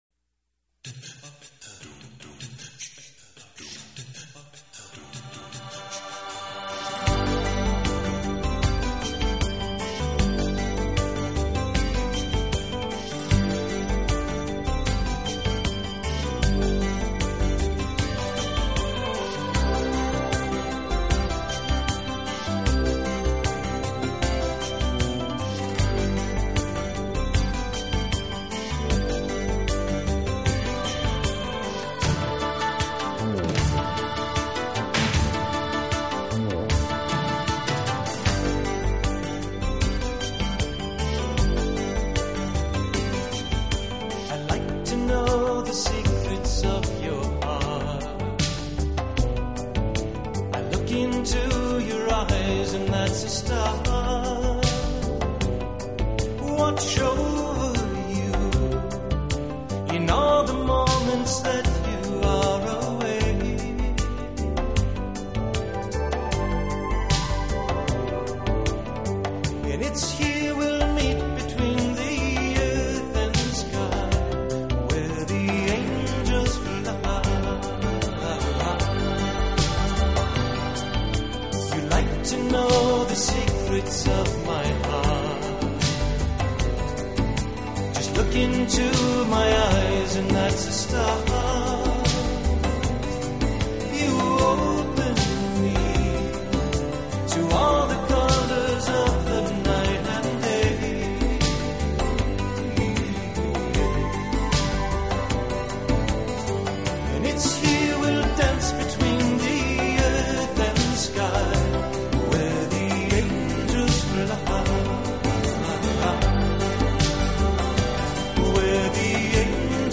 takes us through pieces that are distinctly Greek, electric,
excels on guitar, keyboards and emotional expression.